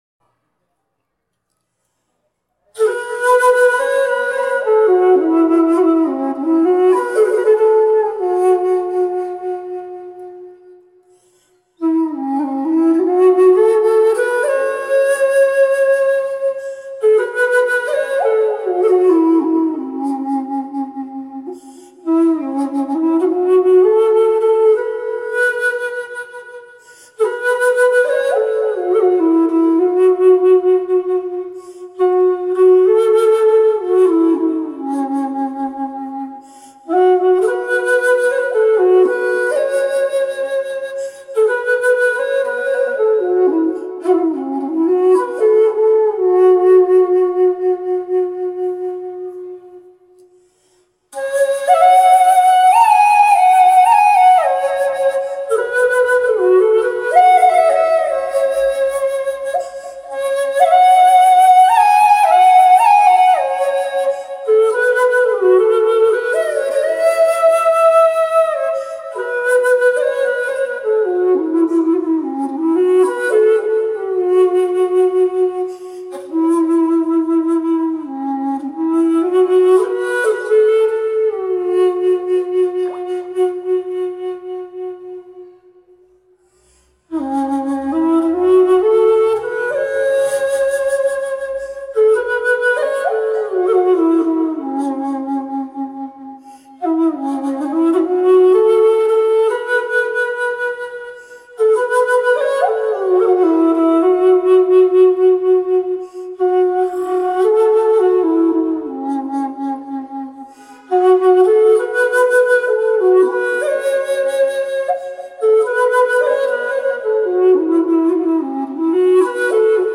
盲人苗家艺人【箫演奏】：《我在高山上》
盲人苗家艺人【箫演奏】：《我在高山上》 2019-06-14 由 天星编辑 发布于： 作品展 , 盲人苗家艺人 , 箫 , 苗族 浏览： 1,925 次 正文： 曲名：《我在高山上》 演奏：盲人苗家艺人 类型：苗族歌曲 点击下载 立即打赏 版权声明：本站的相关作品军属天星版权所有，如若发现有盗链行为，本站将追究其版权责任。